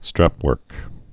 (străpwûrk)